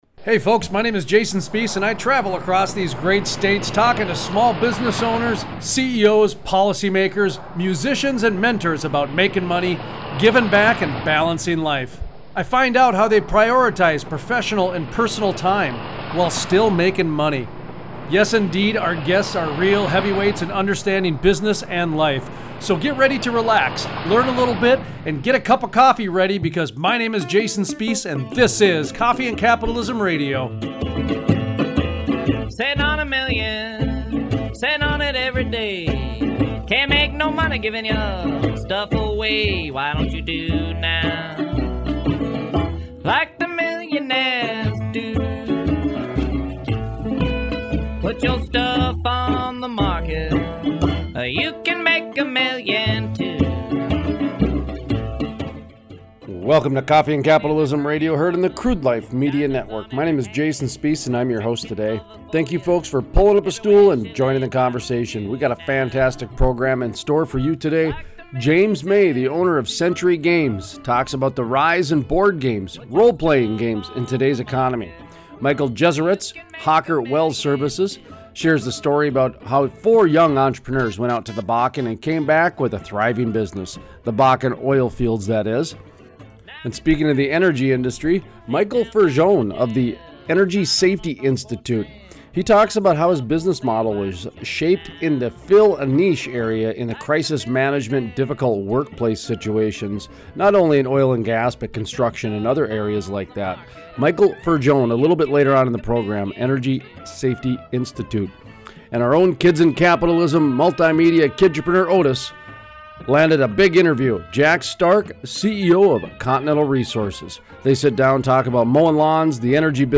Workplace Wellness Interview